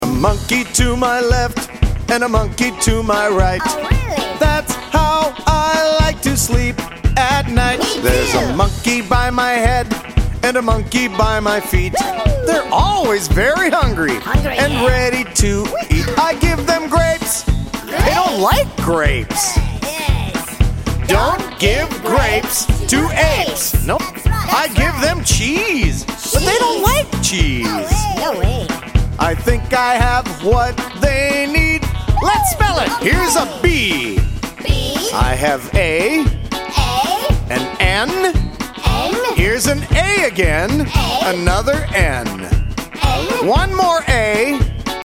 -Kids and adults singing together and taking verbal turns